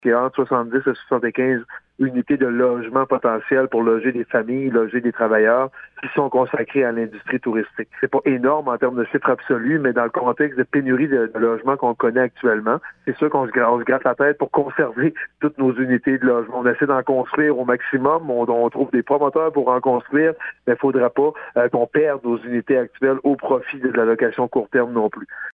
Le maire Daniel côté :